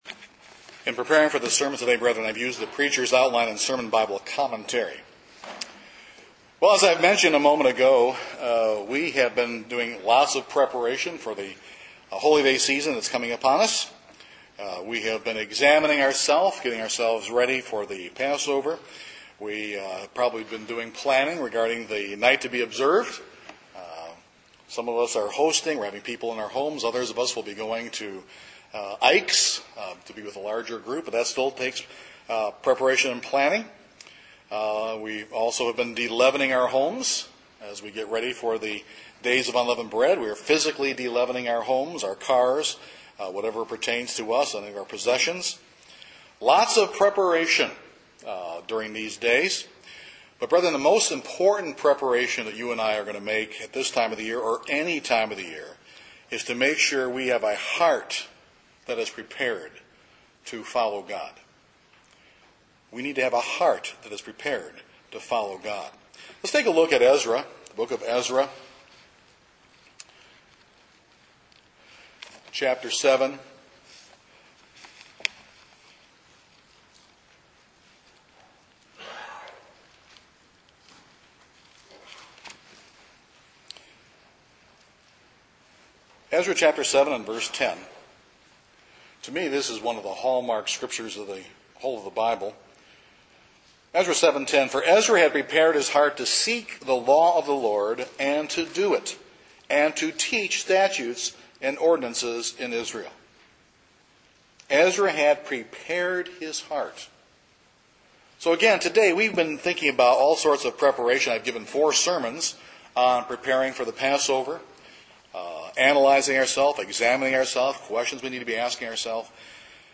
To properly understand and benefit from the rich meaning of God's word and way of life we must have hearts that are prepared. This sermon discusses how it is that we as Christians prepare our hearts to receive the maximum spiritual benefit that God intended.